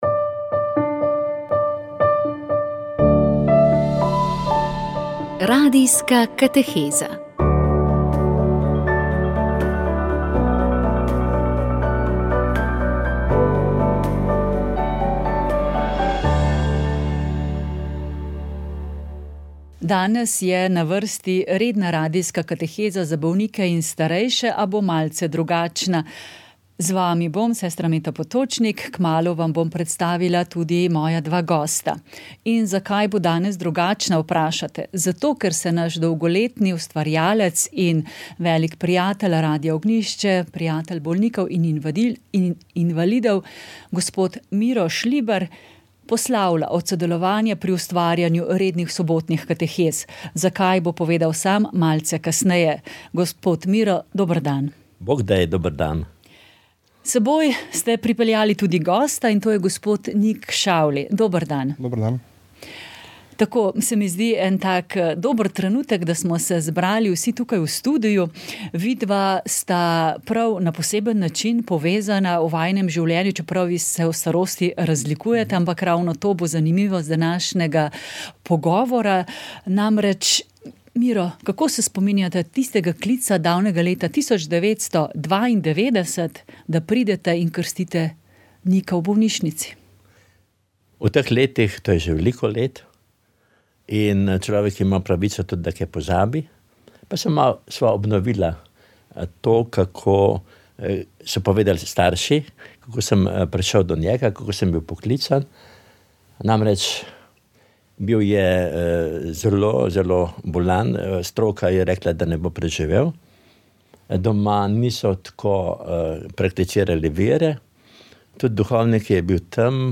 Rožni venec
Molitev je vodil ljubljanski nadškof Stanislav Zore.